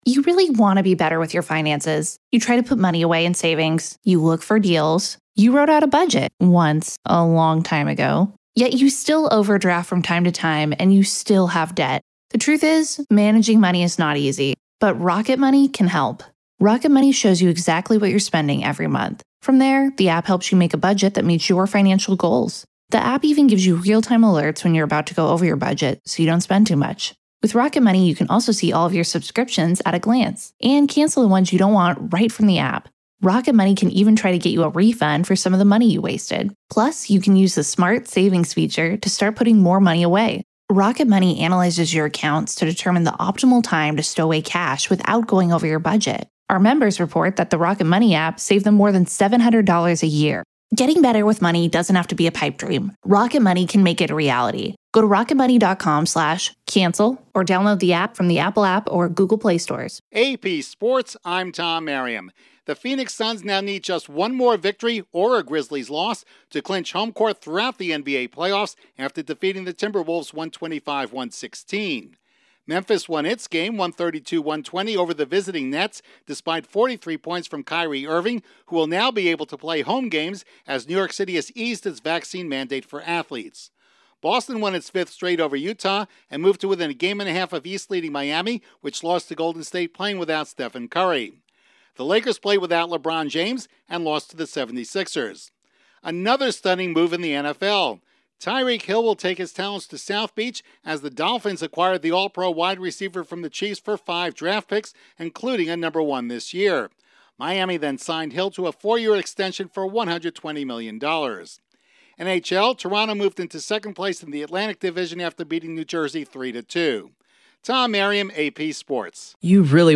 Sports News from the Associated Press / Update on the latest sports